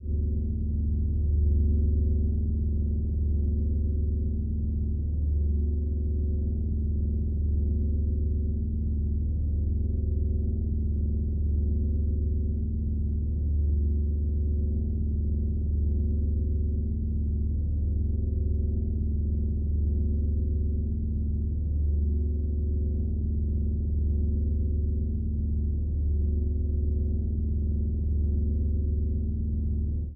The following audio examples consist of processed, transposed and superimposed tubax sounds amplifying tonal interferences.
Audio Example: Drone in D
01_Drone_D.mp3